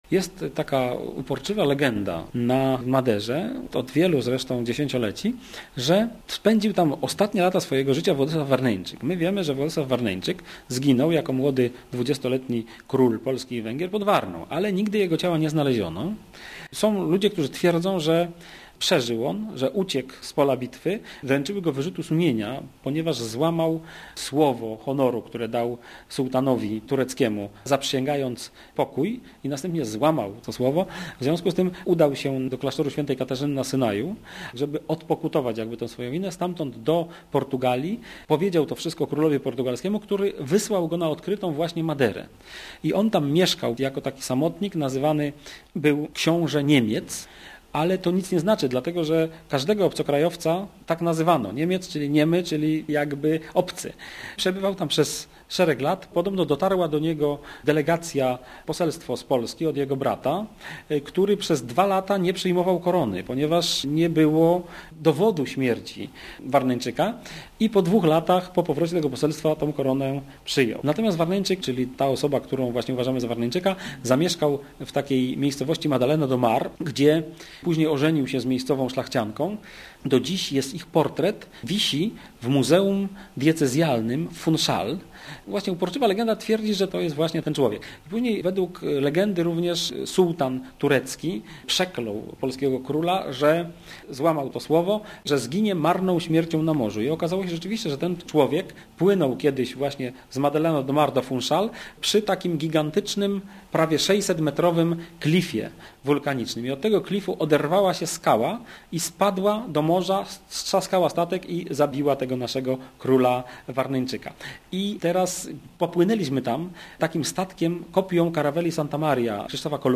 Numer 2/2003 zawiera 10 relacji polskich podróżników z różnych krajów świata: 80 ekranów tekstowych z praktycznymi informacjami i reportażami, 250 minut radiowych opowieści i muzyki etnicznej ilustrowanych slajdami, 1530 podpisa- nych zdjęć oraz 40 minut sekwencji filmowych.